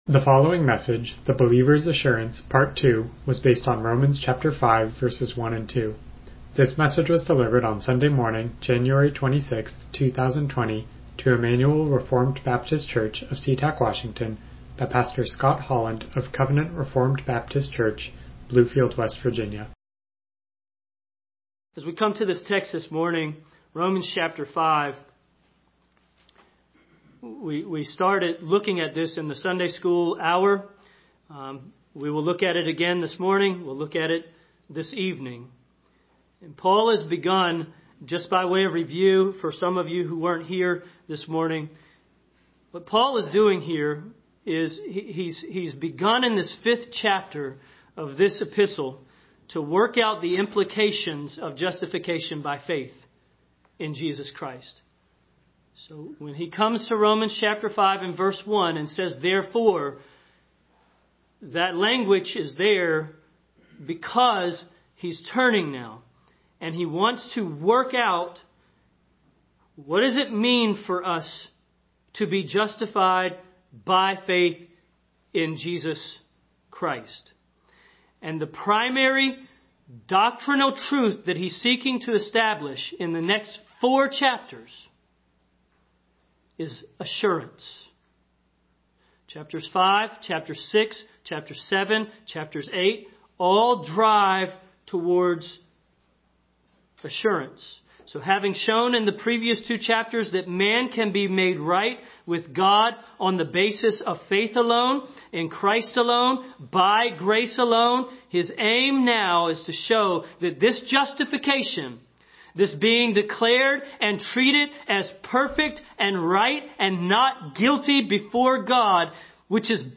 Passage: Romans 5:1-2 Service Type: Morning Worship « The Believer’s Assurance